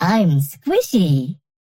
Viscous voice line - I'm squishy!